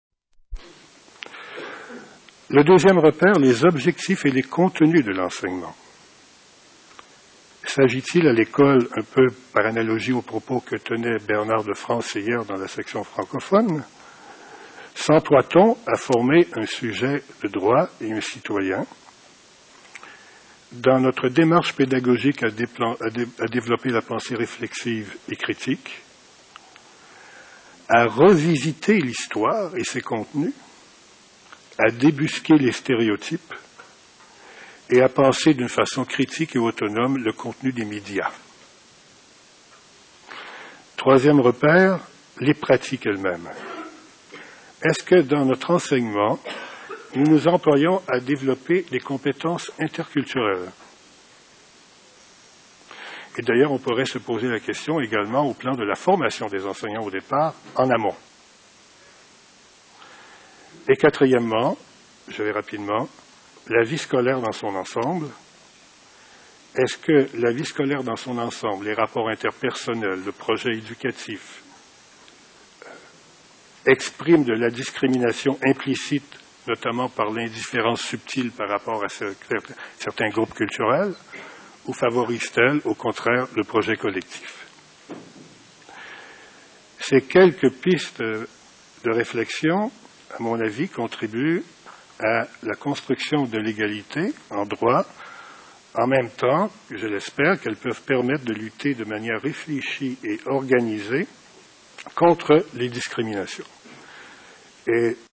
19e session internationale du CIFEDHOP
Genève, du du 8 au 14 juillet 2001